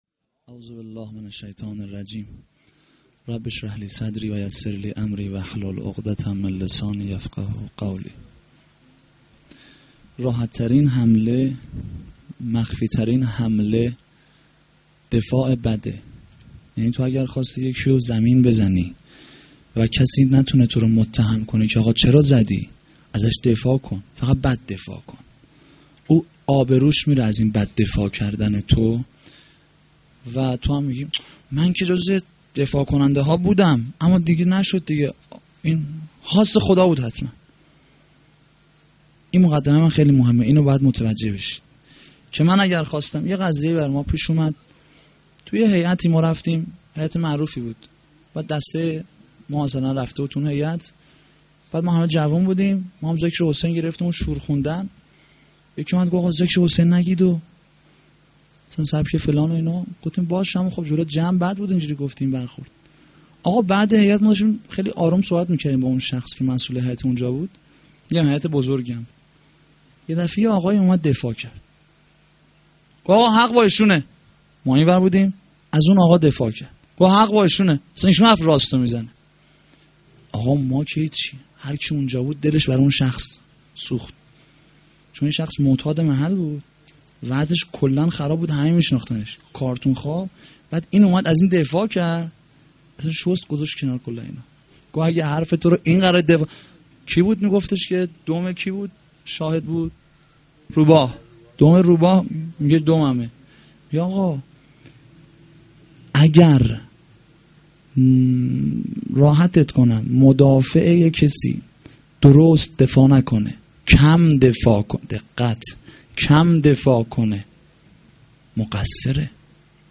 shahadate-h.-zeynab-s-93-sokhanrani.mp3